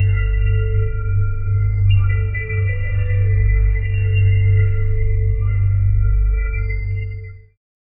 Organic ambient background with soft kitchen sounds, layered with light forest ambiance and a subtle ethereal drone for a mysterious and calm atmosphere. Ideal for artisanal chocolate ad filmed in a cozy kitchen. 0:08 Created Apr 16, 2025 8:52 AM
organic-ambient-backgroun-ckscefna.wav